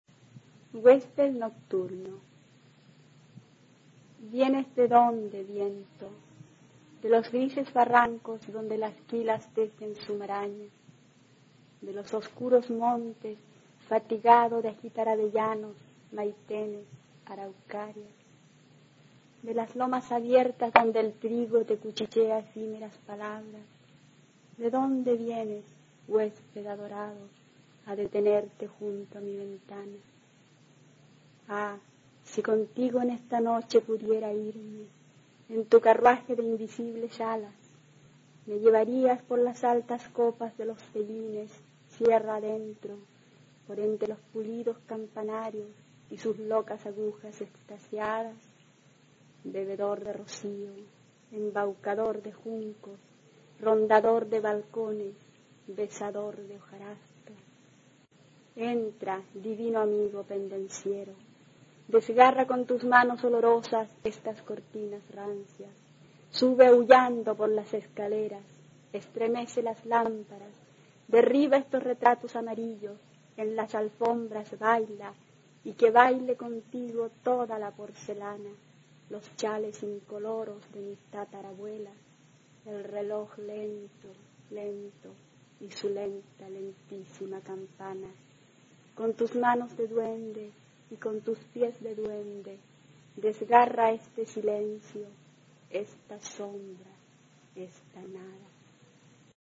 A continuación se puede ecuchar a la escritora chilena Eliana Navarro, miembro del Grupo Fuego de Poesía, recitando su poema Huésped nocturno, del libro "Antiguas voces llaman" (1955).
Poema